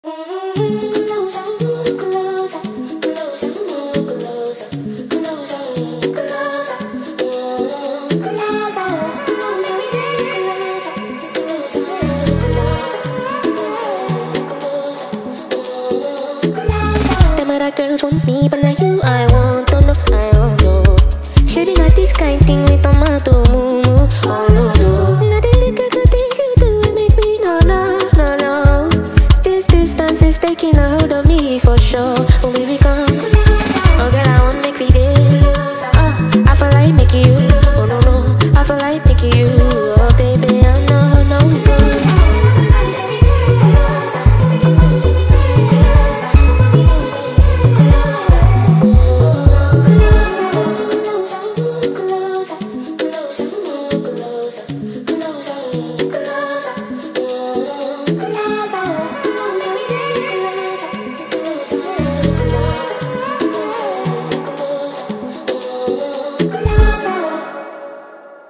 наполненное ритмичным звуком и эмоциональной глубиной.